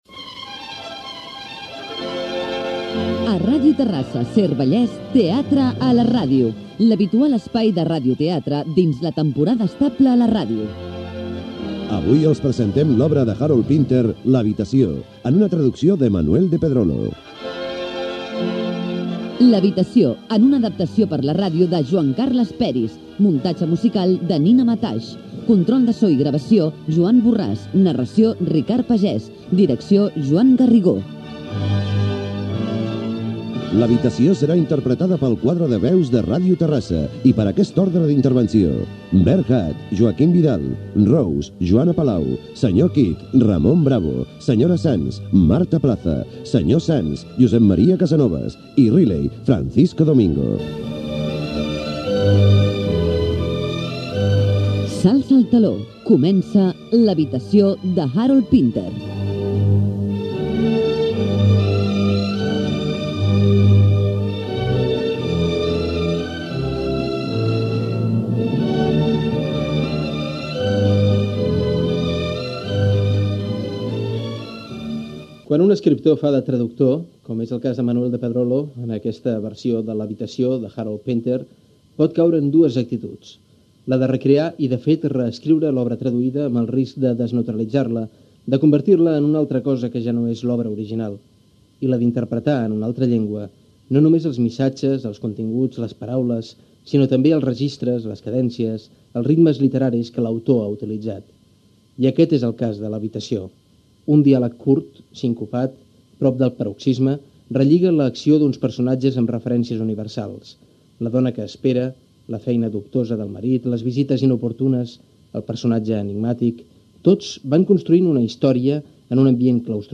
Obra de teatre adaptada per a la ràdio: "L'habitació", d'Harold Pinter. Repartiment de l'obra, presentació de l'obra i escenes inicials.
Ficció